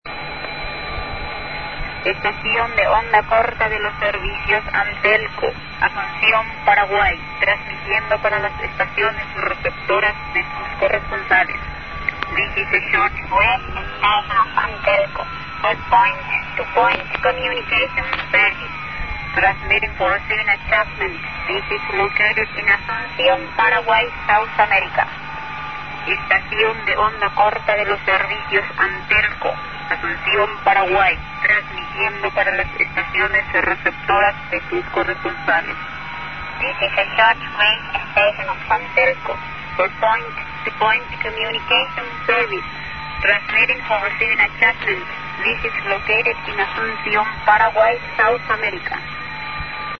a recording from 1972 of their well-known voice mirror